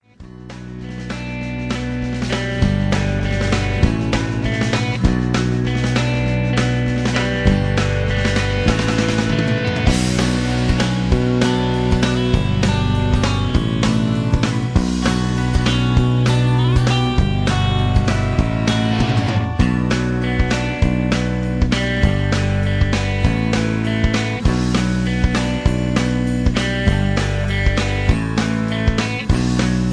Just Plain & Simply "GREAT MUSIC" (No Lyrics).